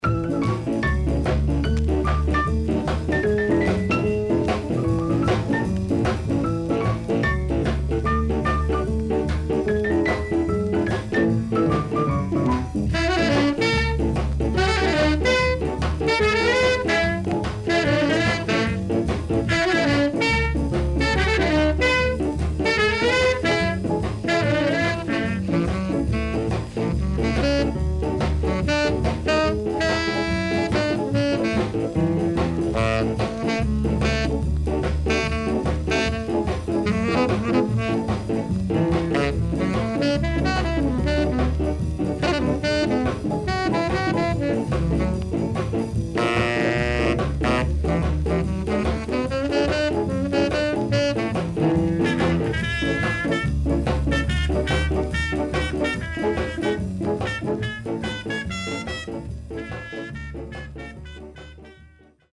Vibraphoneが印象的で穏やかなNice Ska Inst.W-Side Good
SIDE A:少しチリノイズ、プチノイズ入ります。